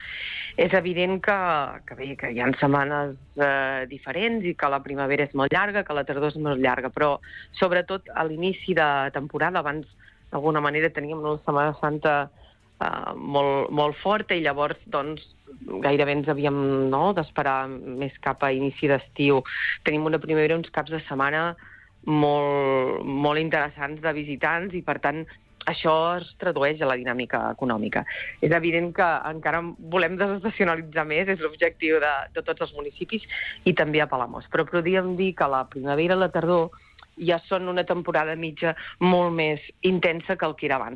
L’alcaldessa de Palamós, Maria Puig, ha valorat positivament les primeres setmanes al càrrec, en una entrevista concedida al Supermatí de Ràdio Capital.